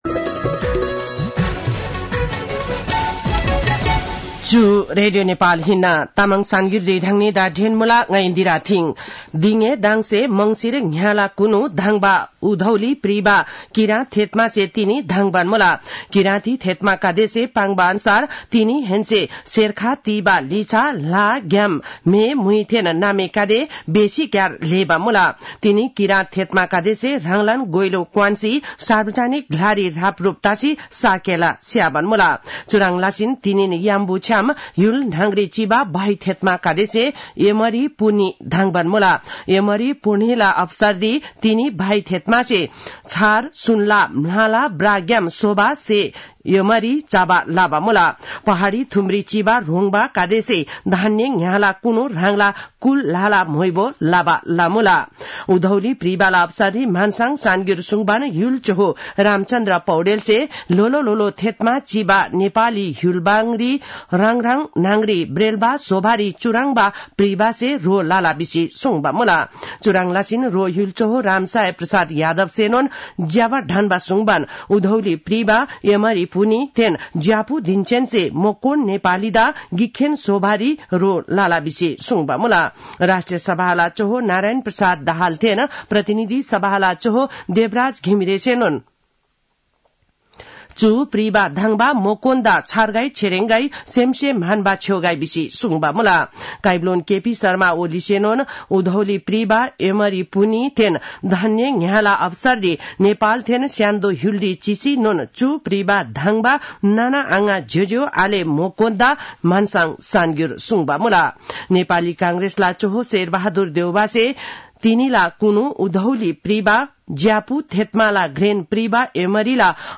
तामाङ भाषाको समाचार : १ पुष , २०८१
Tamang-News-8-30.mp3